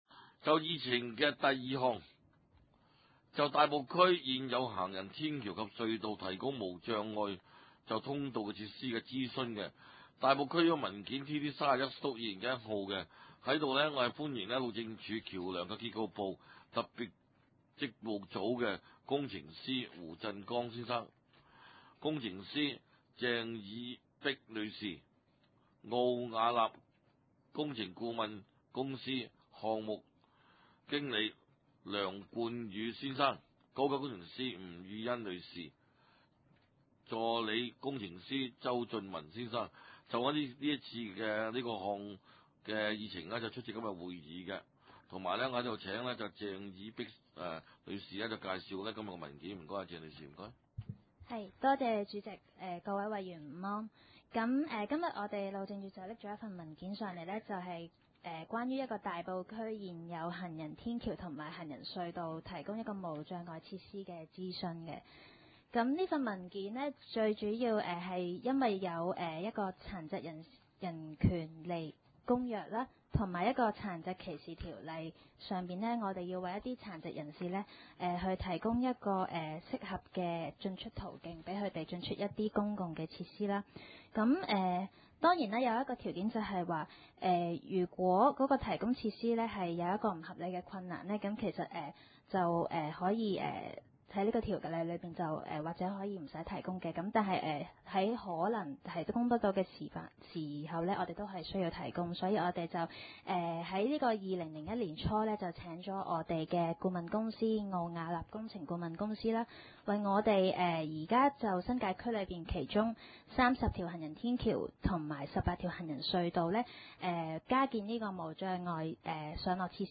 大埔區議會 交通及運輸委員會 2 011 年第四次會議 日期：2011年7月15日 (星期五) 時間：下午2時30分 地點：大埔區議會秘書處會議室 議 程 討論時間 I. 通過交通及運輸委員會 2011 年 5 月 13 日第三次會議紀錄 00:39 ( 大埔區議會文件 TT 30/2011 號 ) II. 大埔區現有行人天橋及隧道提供無障礙通道設施的諮詢 41:53 ( 大埔區議會文件 TT 31/2011 號 ) 33 :19 35:27 III.